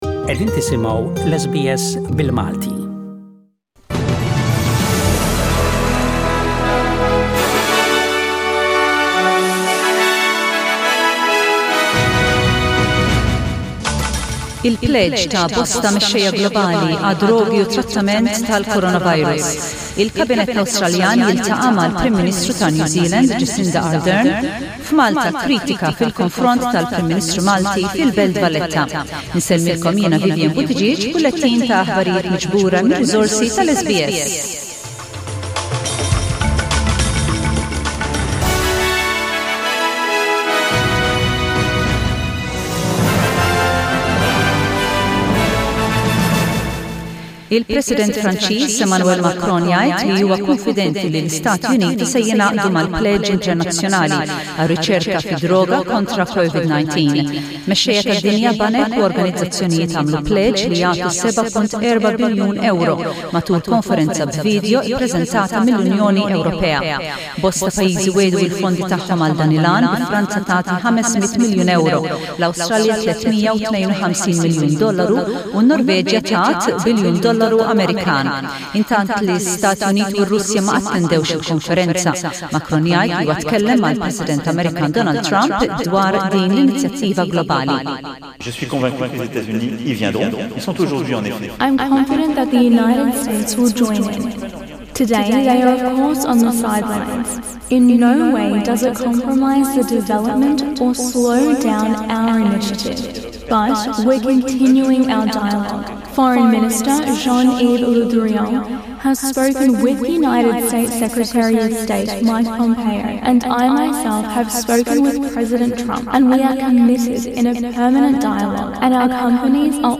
SBS Radio | News in Maltese: 05/05/20